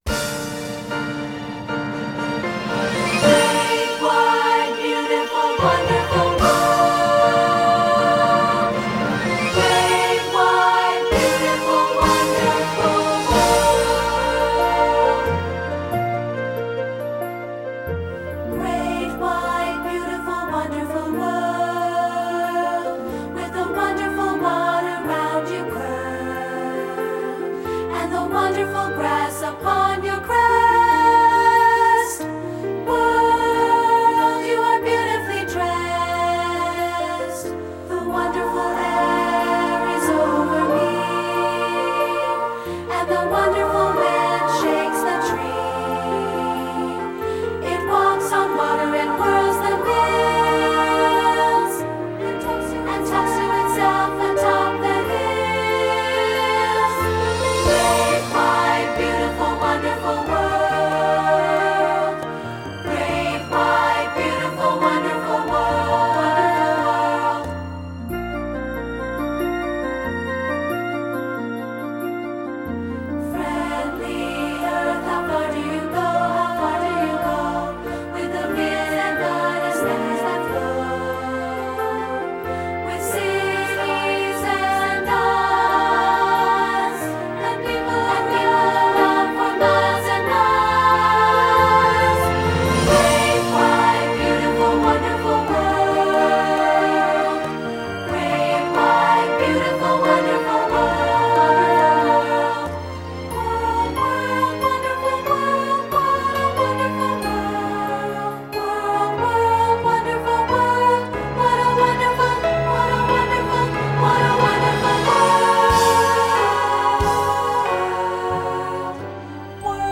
secular choral
2-part recording